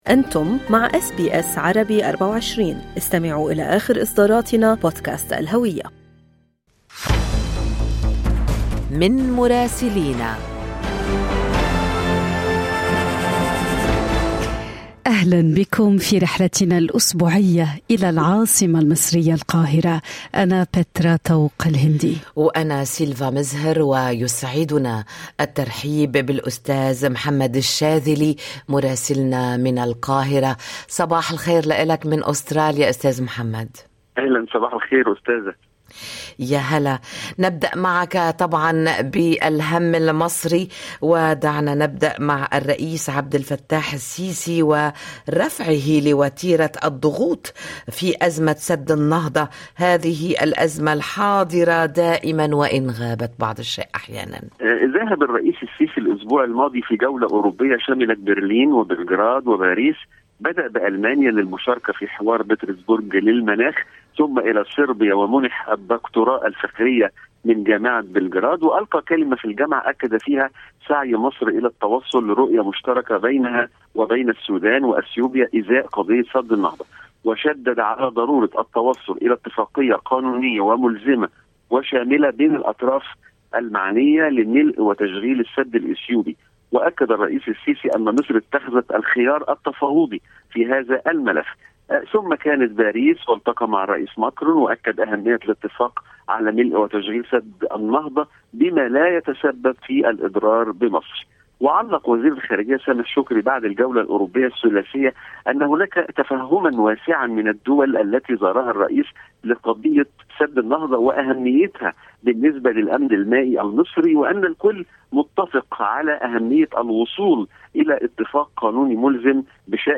من مراسلينا: أخبار مصر في أسبوع 27/7/2022
أهم أخبار الدول العربية مع مراسلينا من لبنان ومصر والأراضي الفلسطينية والعراق والولايات المتحدة.